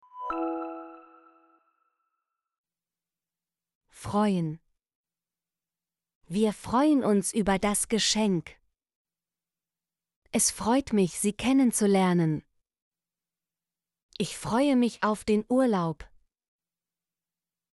freuen - Example Sentences & Pronunciation, German Frequency List